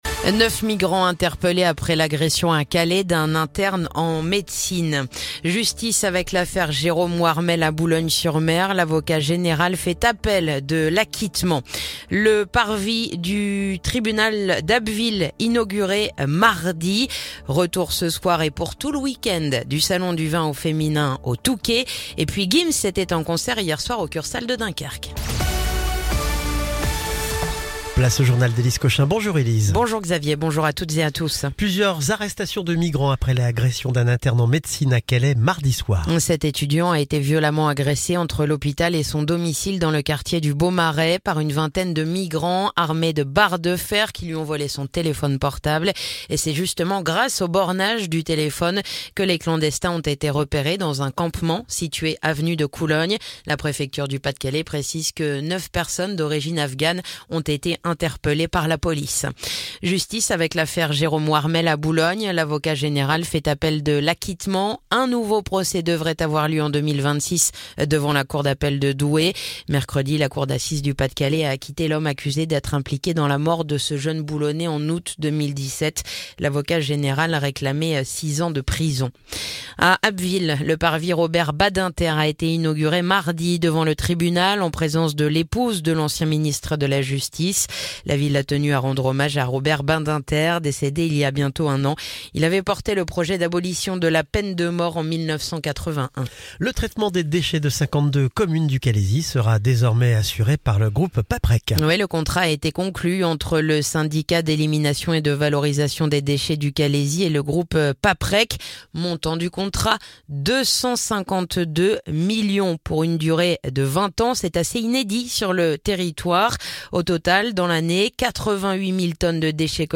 Le journal du vendredi 31 janvier